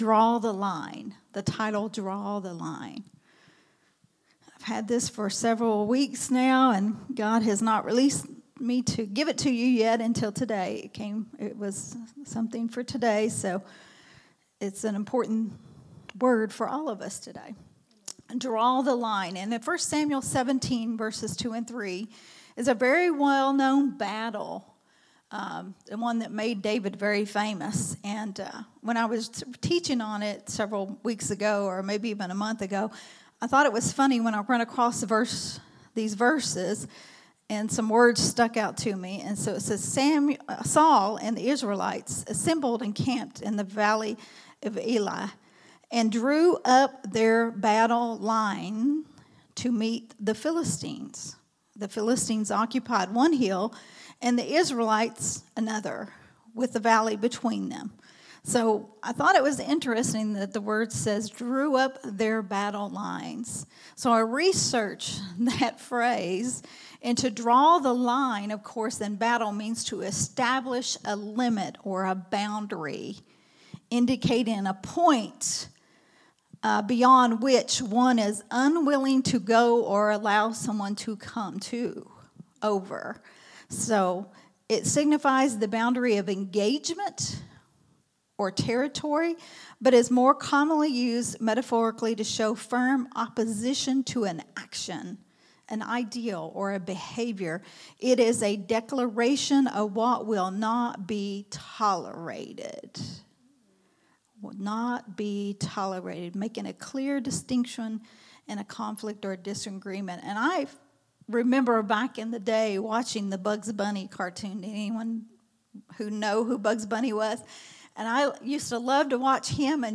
recorded at Growth Temple Ministries on Sunday